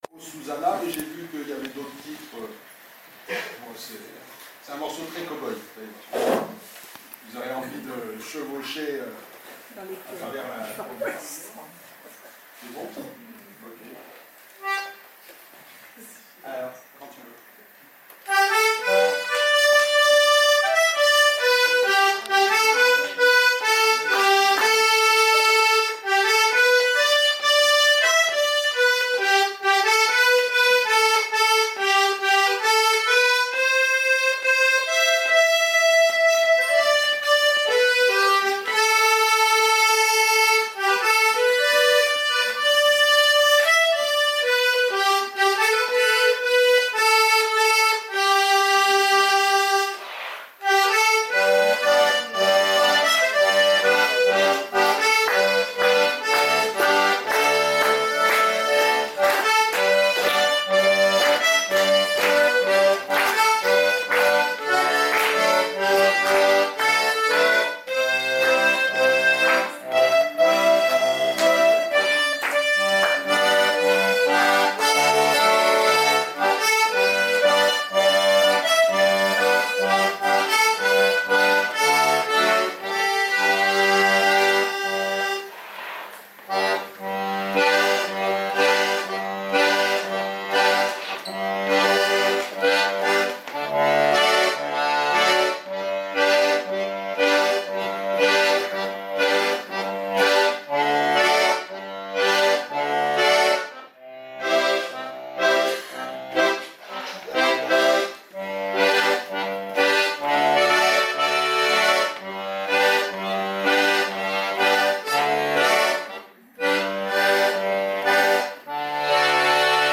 ACCORDÉON DIATONIQUE SAMEDI 9 FÉVRIER 2019
Un voyage musical d’accordéons diatoniques et de guitares au cours d’une soirée